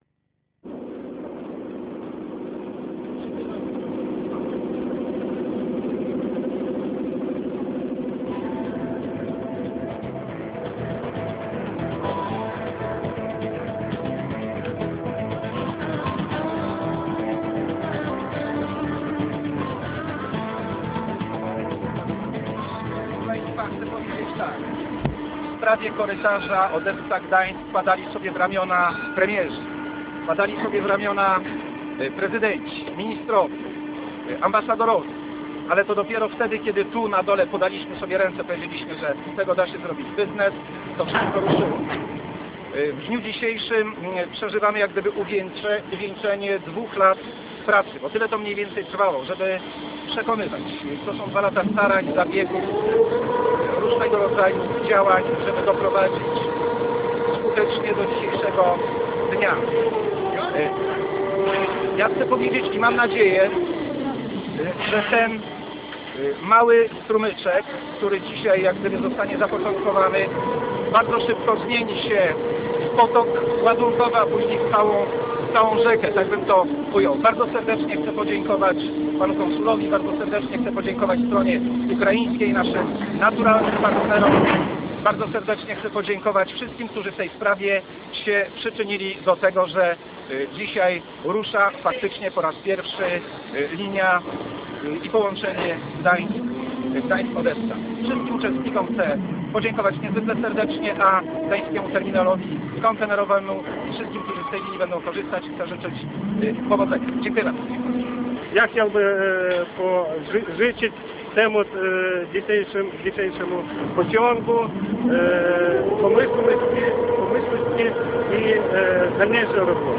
Film z uroczystości otwarcia Korytarza Transportowego Gdańsk Odessa: